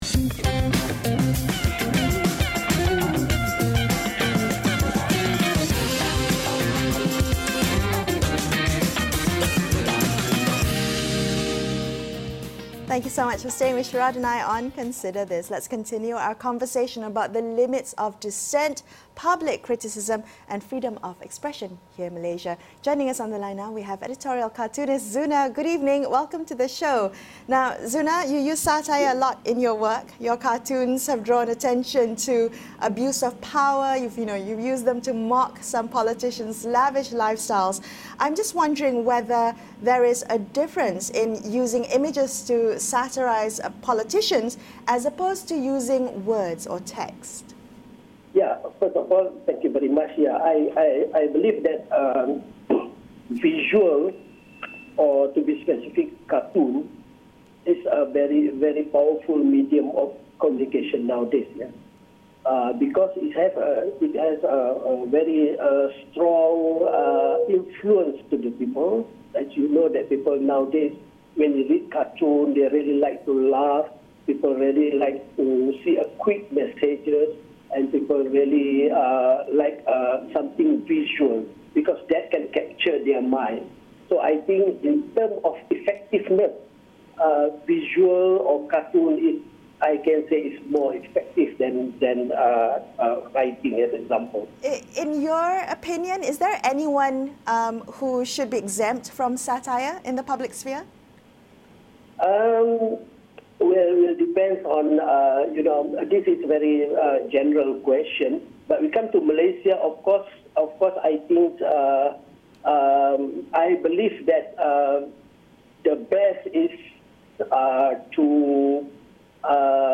speak to award-winning editorial cartoonist Zunar